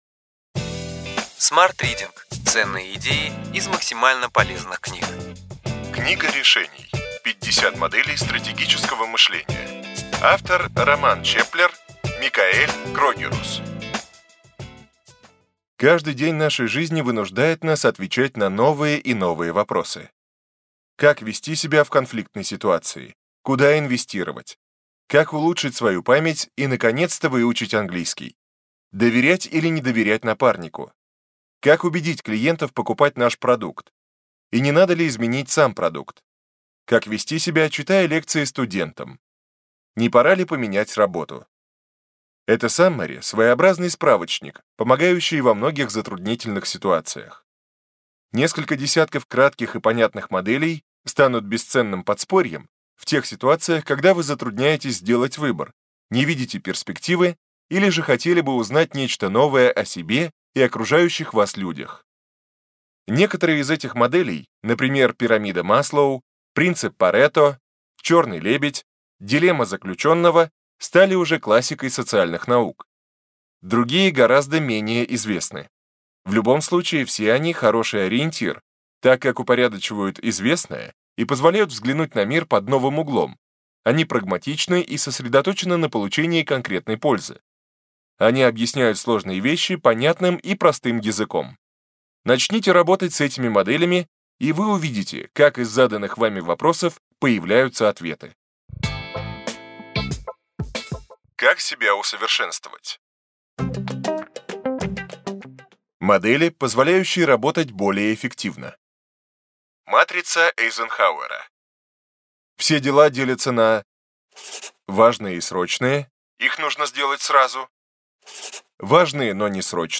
Аудиокнига Ключевые идеи книги: Книга решений. 50 моделей стратегического мышления.